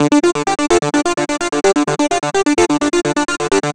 Transistor Shell Dm 128.wav